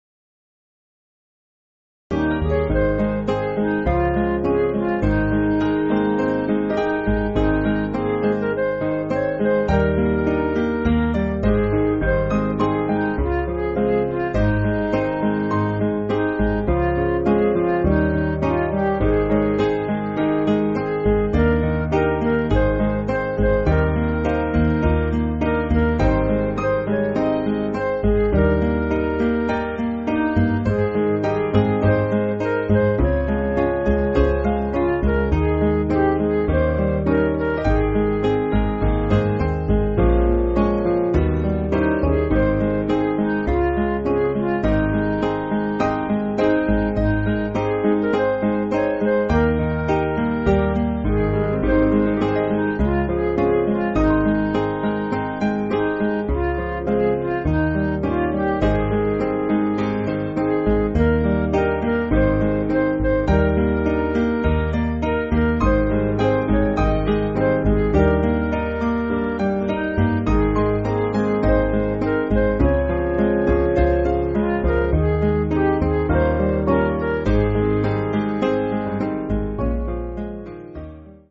Piano & Instrumental
(CM)   3/Ab
Midi